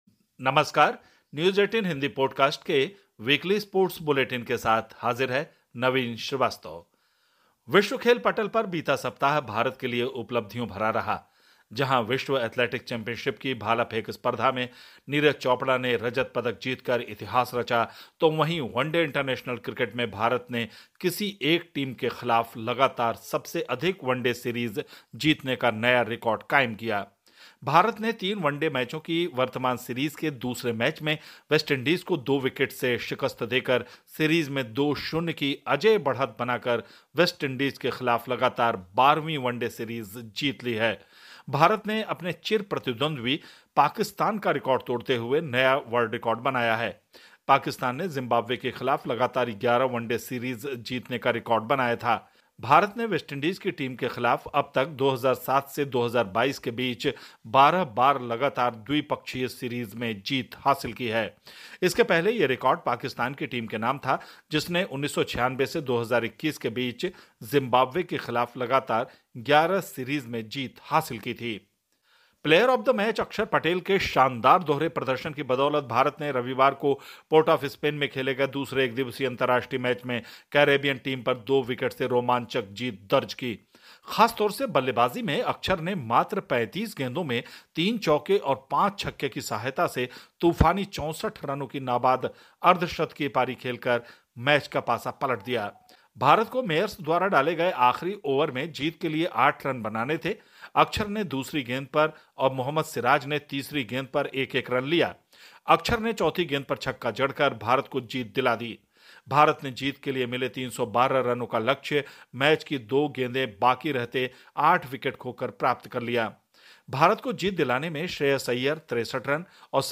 स्पोर्ट्स बुलेटिन / Podcast Weekly: भारत ने तोड़ा पाकिस्तान का रिकॉर्ड, वेस्टइंडीज पर दर्ज की ऐतिहासिक जीत